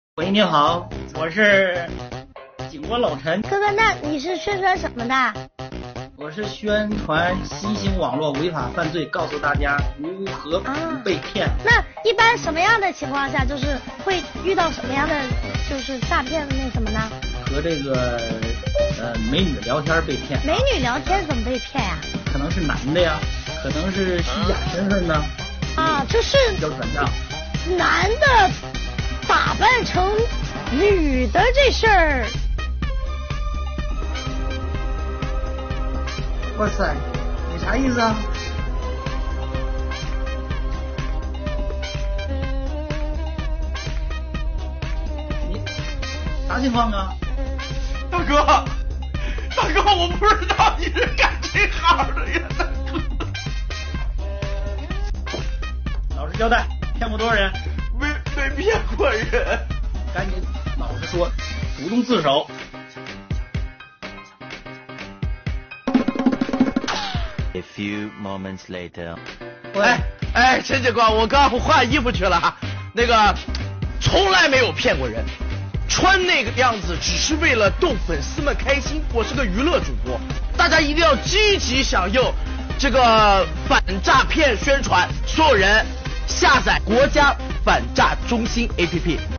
“女主播”还嗲嗲地问：